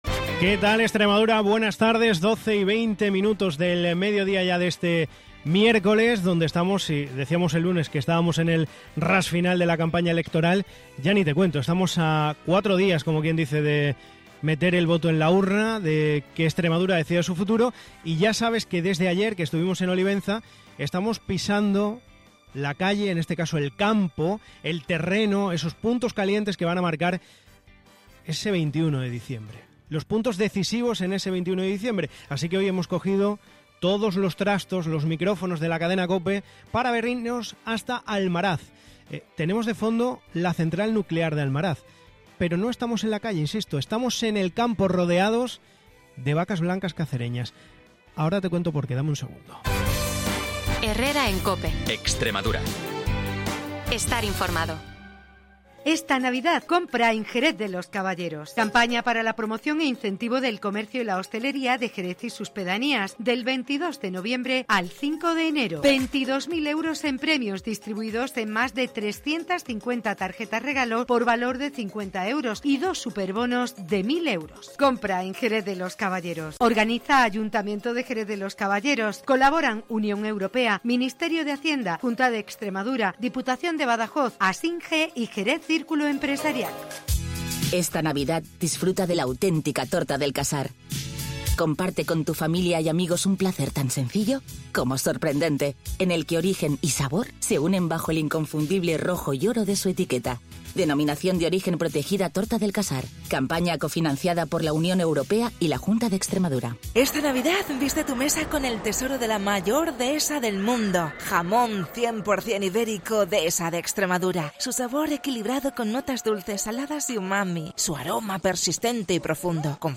En una programación especial desde la comarca del Campo Arañuelo , la Cadena COPE ha pulsado la opinión de ganaderos y trabajadores que viven directamente de la planta, cuyo cierre sigue vigente y previsto para 2027 , a pesar de la controversia política y la dependencia económica de toda la zona.